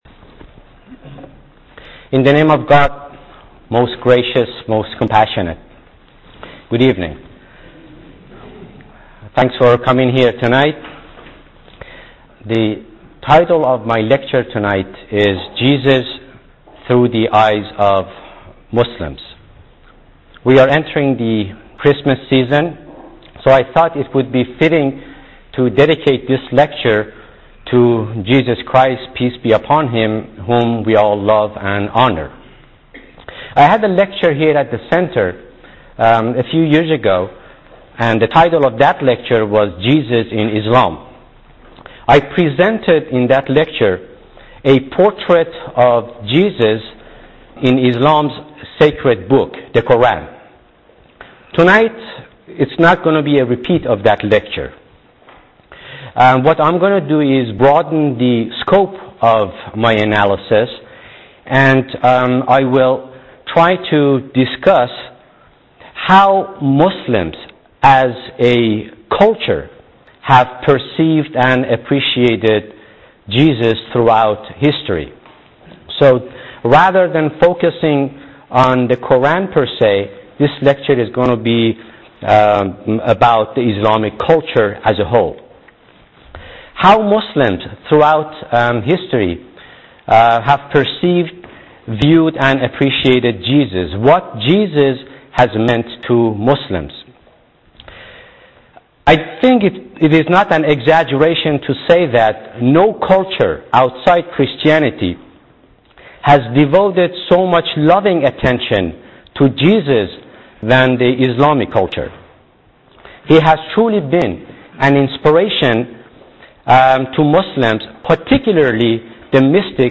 In this lecture, we will draw a sketch of the contours of the Muslim perception of Jesus Christ.